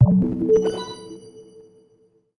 wheel_appear_01.mp3